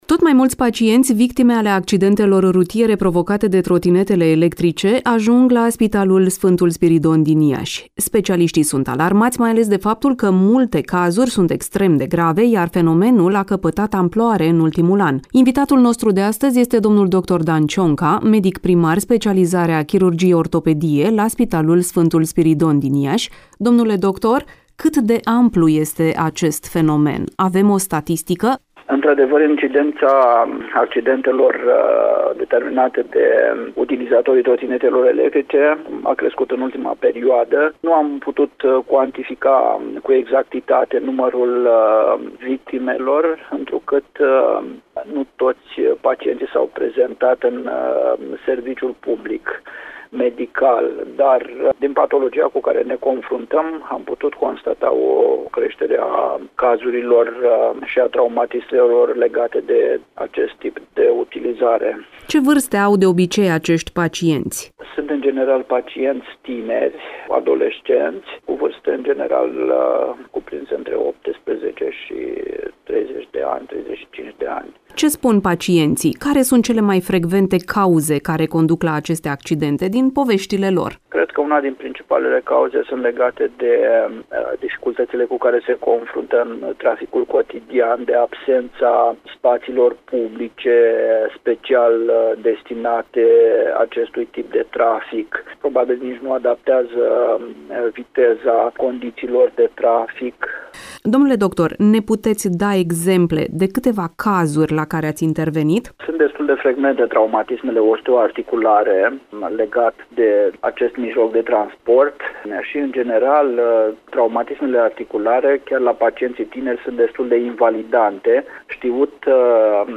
(INTERVIU) Medicii trag un semnal de alarmă! Trotinetele electrice fac tot mai multe victime în trafic - Radio Iaşi – Cel mai ascultat radio regional - știri, muzică și evenimente